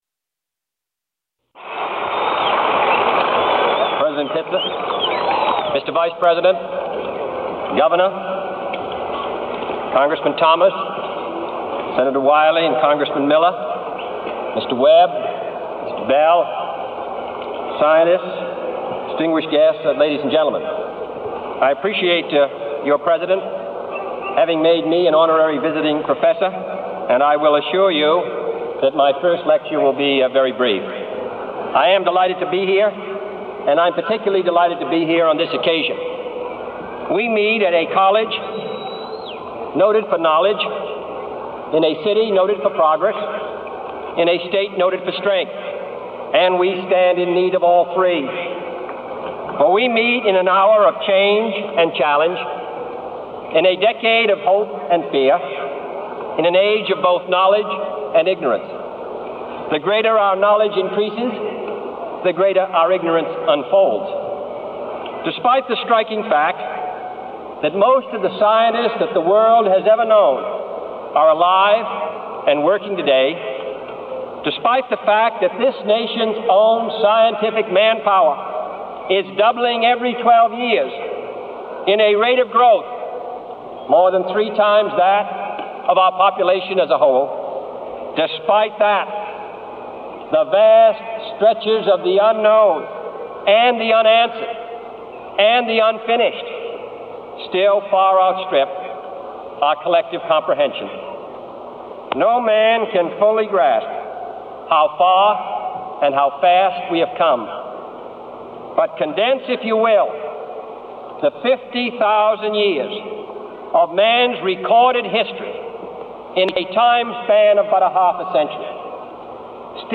Address at Rice University on the Nation's Space Program by John F. Kennedy on Free Audio Download
This Narrator: John F. Kennedy